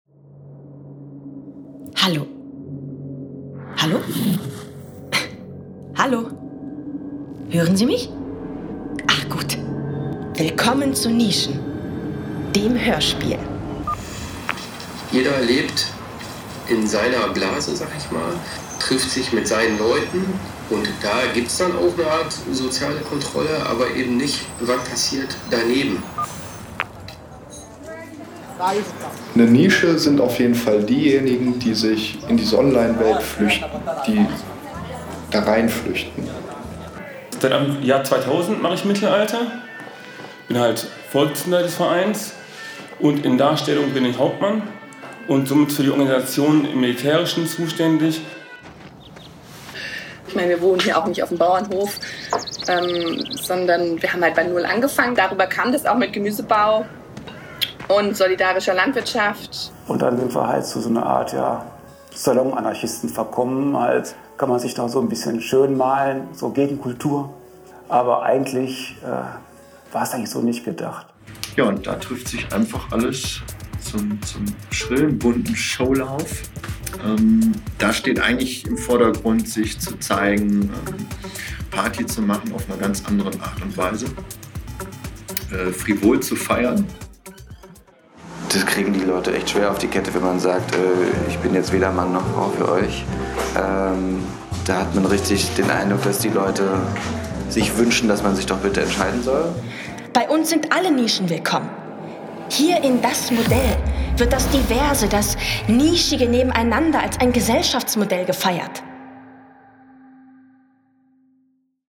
Aus Interviews mit Menschen, die ihre Nische gefunden oder verloren haben entsteht zwischen Wallzentrum und Meerbeck eine neue Kartografie der Stadt.
Dabei verdichten sich Stimmen, Begegnungen und Orte zu neuen Erfahrungsräumen und ermöglichen neue Perspektiven auf die versteckten Seiten der Stadt.
(Corona Version) NISCHEN – das Hörspiel.